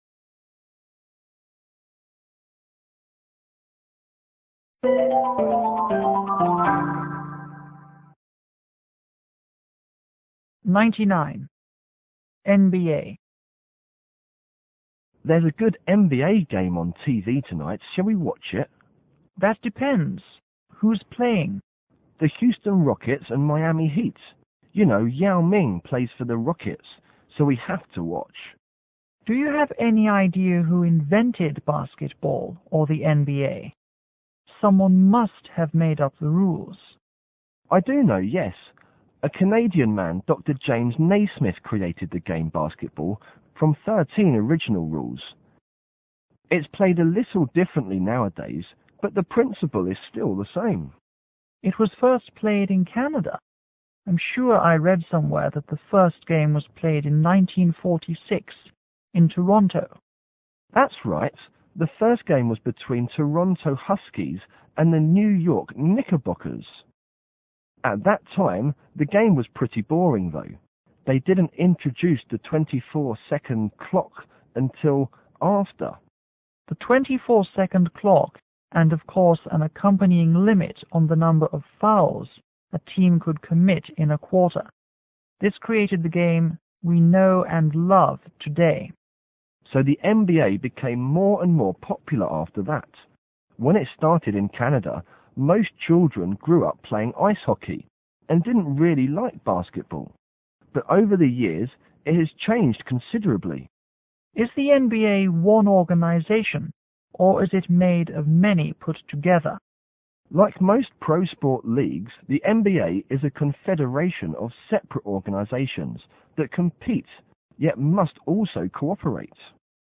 M1 :  Man 1        M2 : Man 2